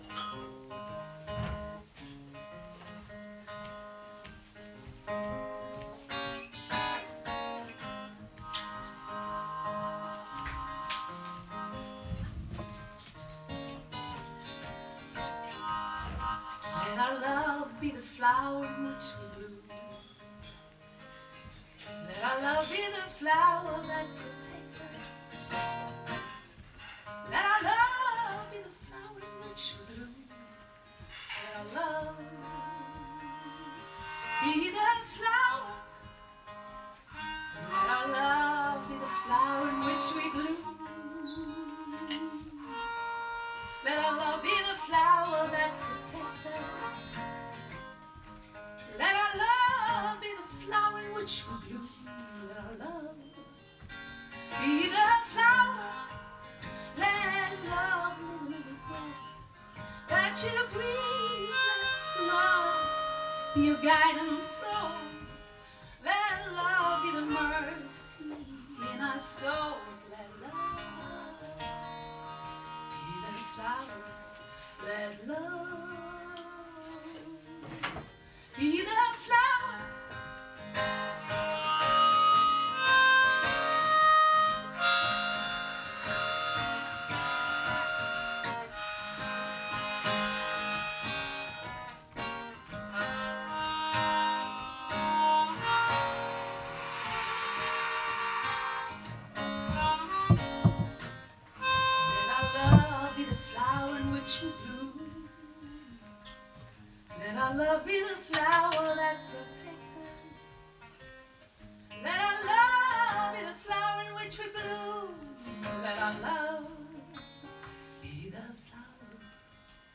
blues och visa